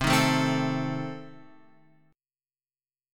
C Minor 6th